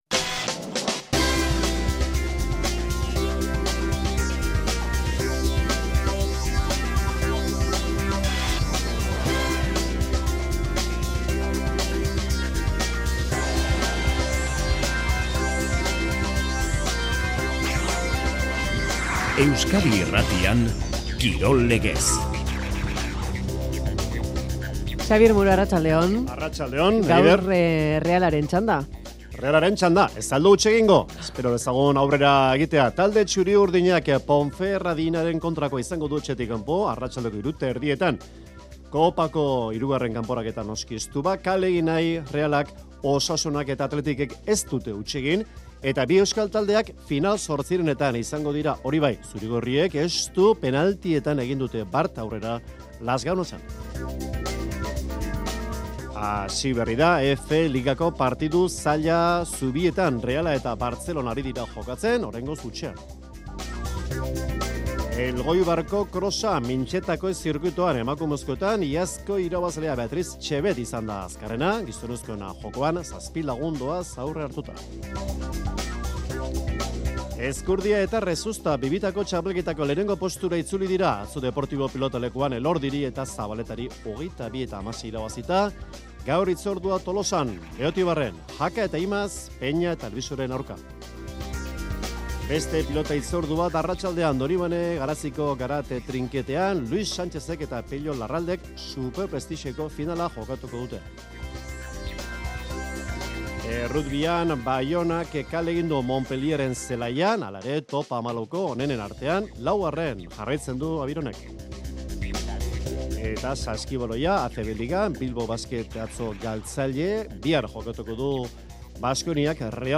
Albisteak, elkarrizketak eta gure kirolaren inguruan jakin beharreko guztiak, adituen iritziak barne